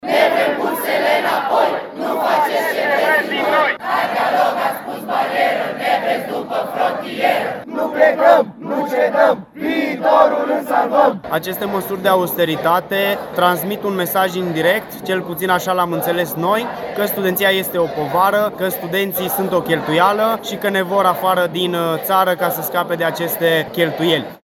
De la Timișoara, studenții au mers la un protest în Vama Nădlac din Arad, unde au scandat că prin măsurile luate, Guvernul îi forțează să plece din țară